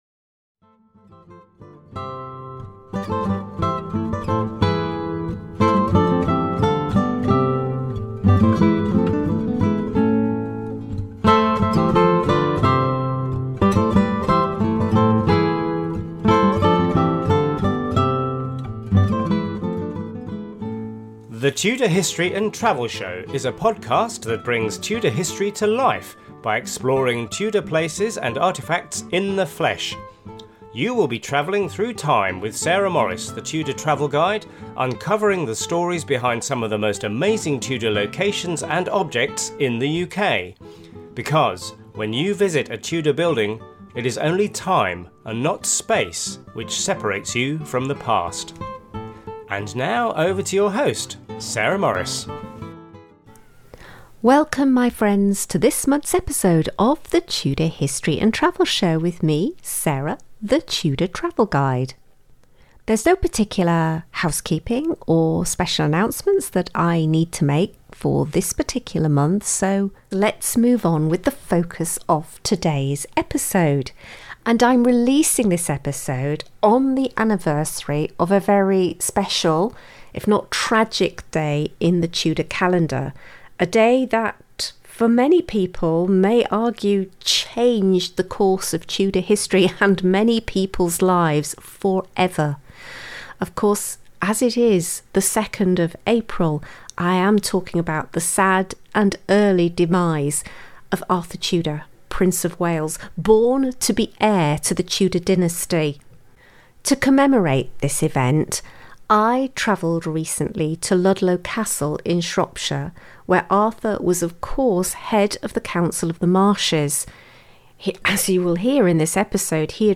A podcast that brings Tudor history to life by exploring Tudor places and artefacts in the flesh. The Tudor Travel Guide brings you lively onsite walk-and-talk interviews with local guides and experts at historic Tudor locations across the UK, creating inspiring ideas for your next Tudor-themed vacation.